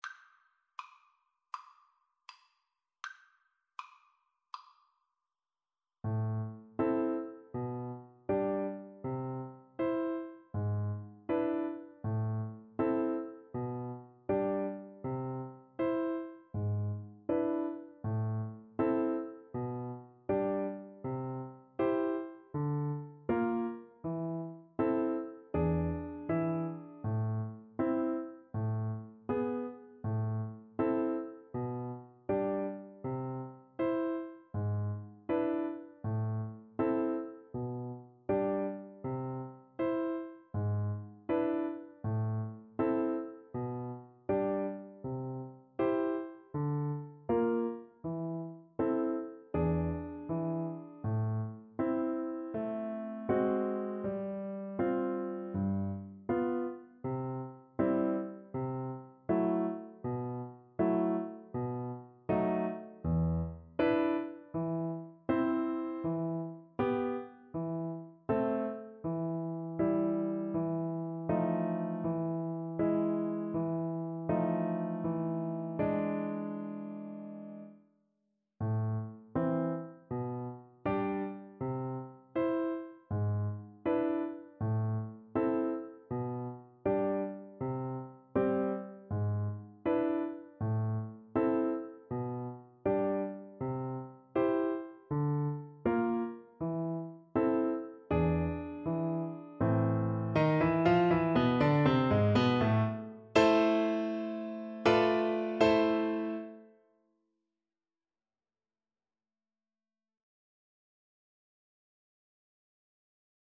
Play (or use space bar on your keyboard) Pause Music Playalong - Piano Accompaniment Playalong Band Accompaniment not yet available reset tempo print settings full screen
Andante = c.80
A minor (Sounding Pitch) (View more A minor Music for Recorder )
Classical (View more Classical Recorder Music)